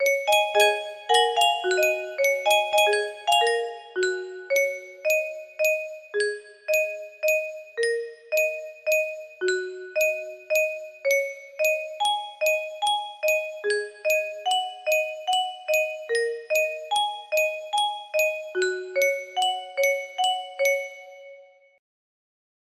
Yay! It looks like this melody can be played offline on a 30 note paper strip music box!
Grand Illusions 30 music boxes More